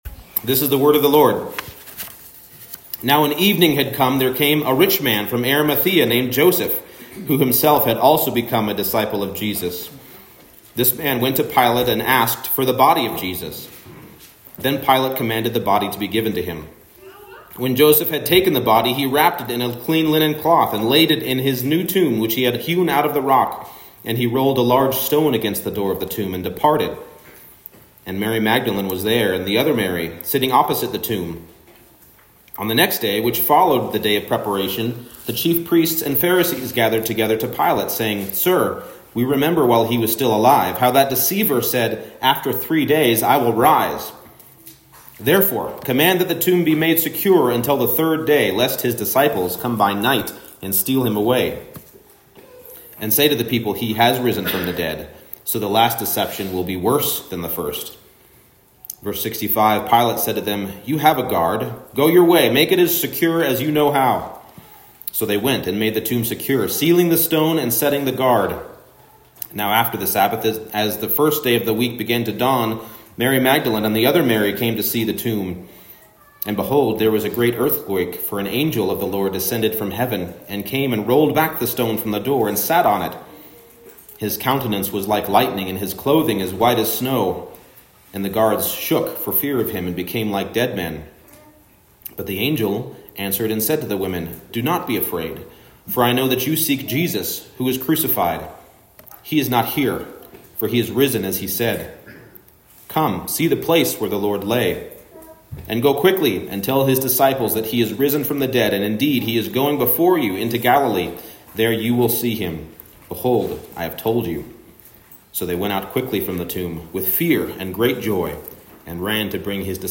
Matthew 27:57-28:8 Service Type: Morning Service Jesus died